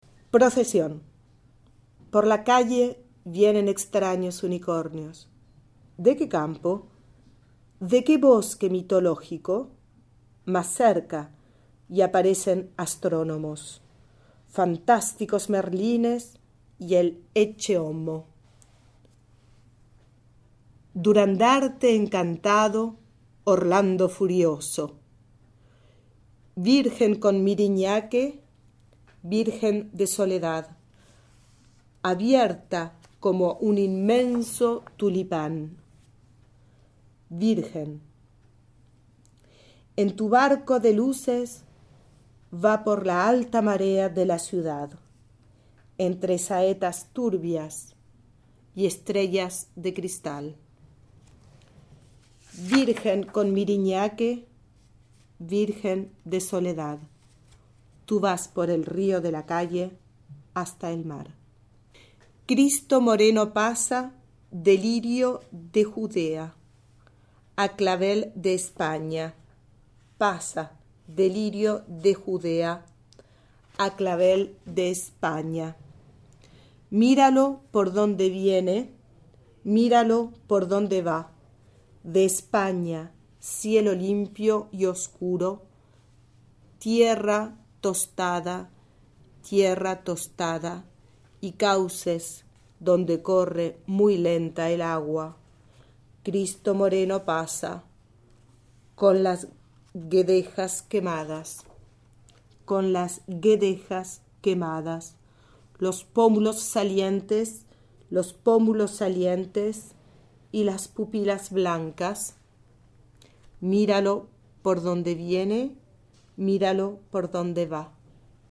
Romancero Gitano  Op. 152                                                      Live